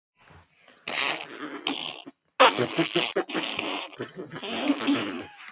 Description: wet poop pop